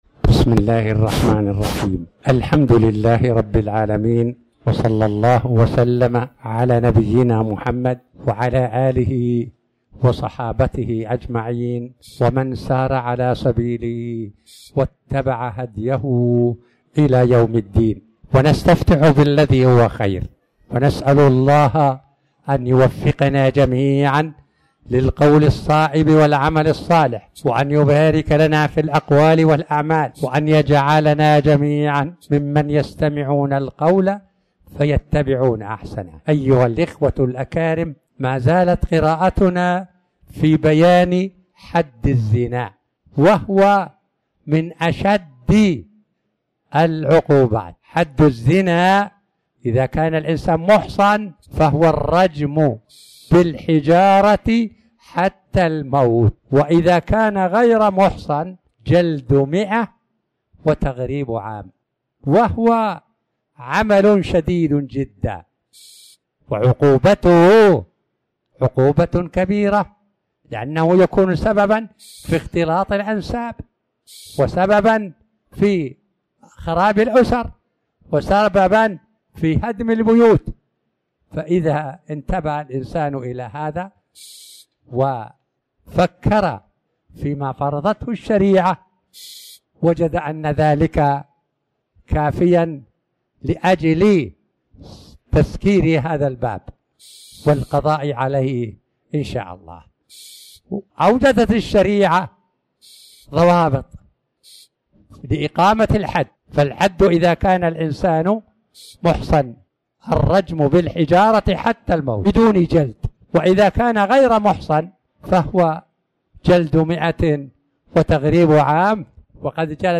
تاريخ النشر ٢٠ ربيع الأول ١٤٤٠ هـ المكان: المسجد الحرام الشيخ